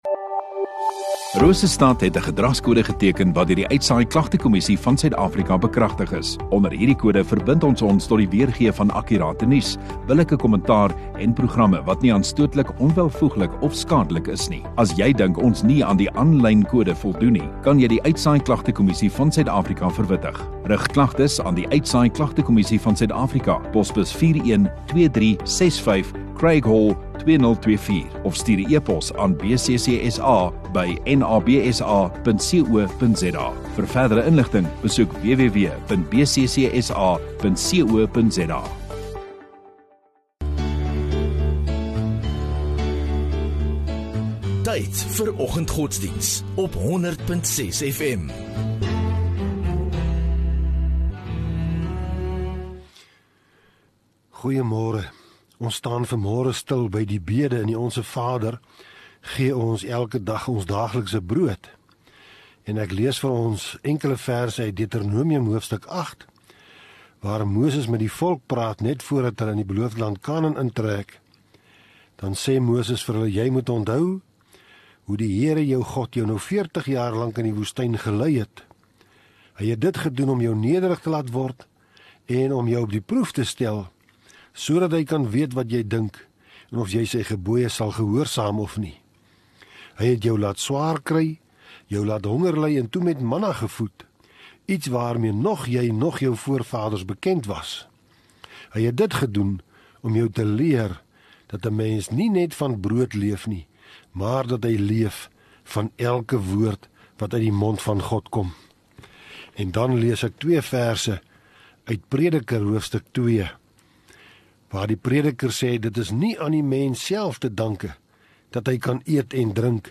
26 Sep Vrydag Oggenddiens